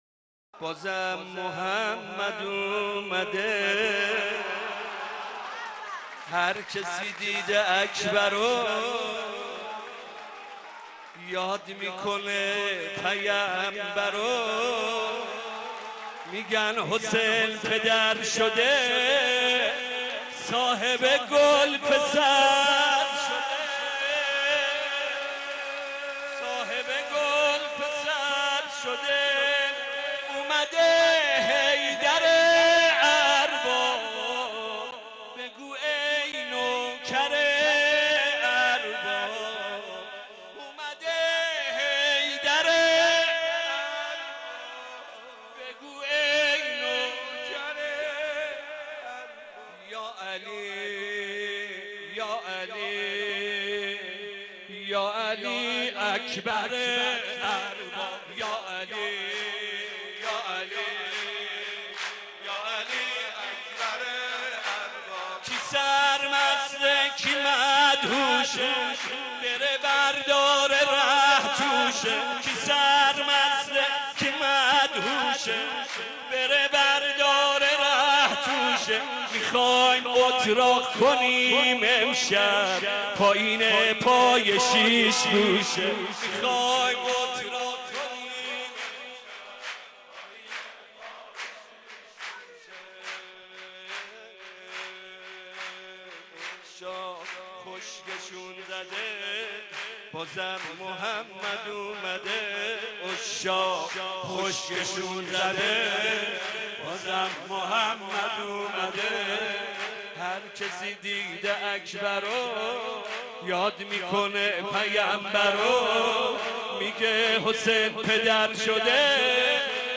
صوت مداحی این مراسم در ادامه منتشر می شود: